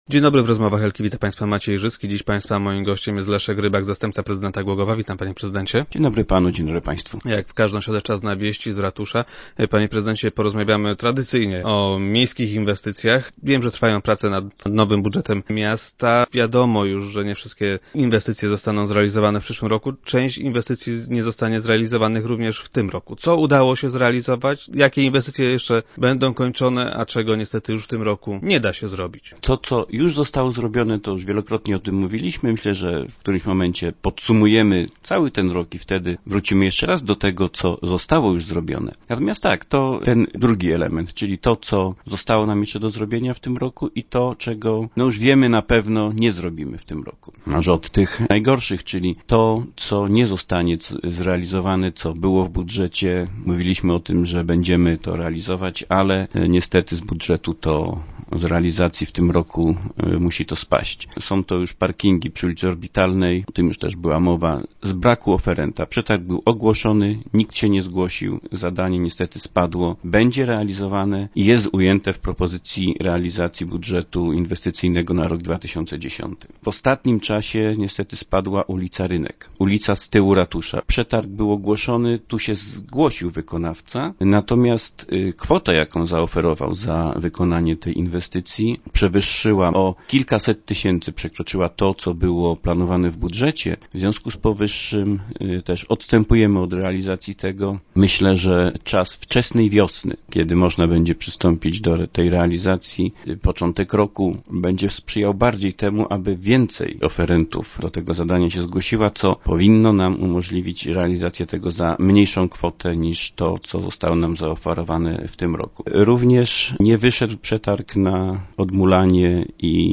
Jak powiedział Leszek Rybak, zastępca prezydenta Głogowa, który był dziś gościem Rozmów Elki, nie wszystkie zostaną w terminie zakończone.